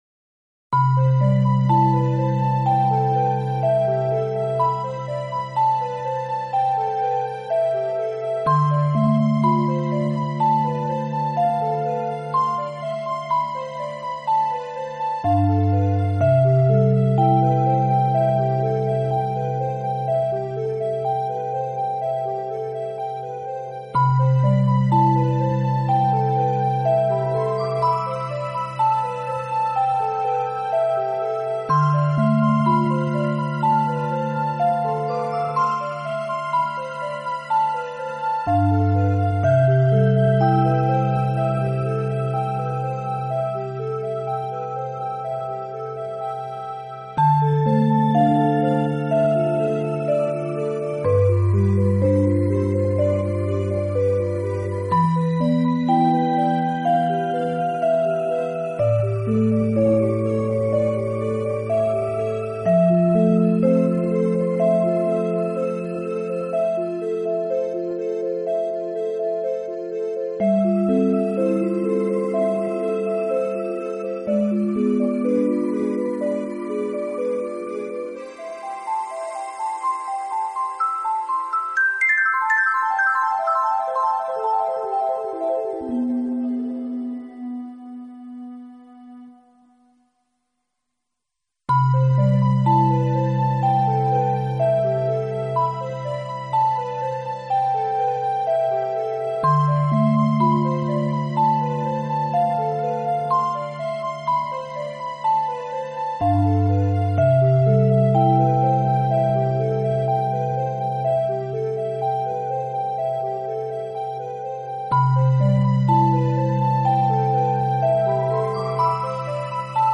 给予柔性的抚慰调节；